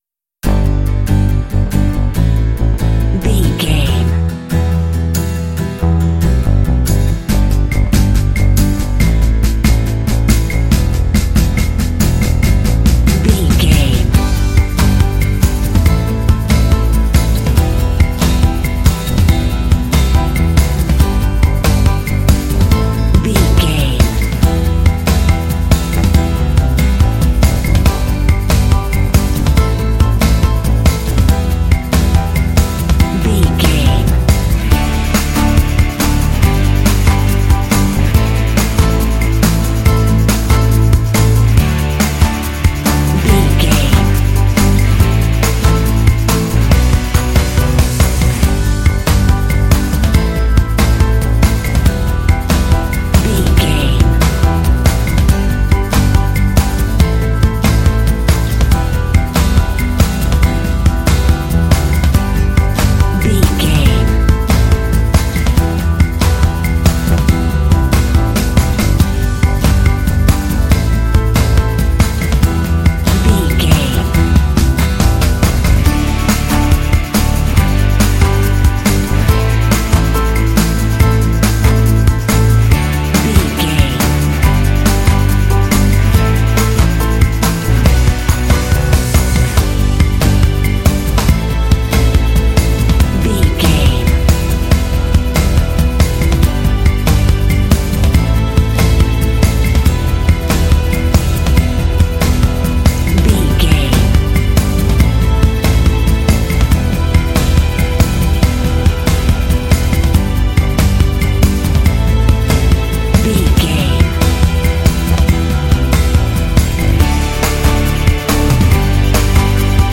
Uplifting
Ionian/Major
Fast
confident
energetic
acoustic guitar
bass guitar
drums
strings
piano
contemporary underscore
rock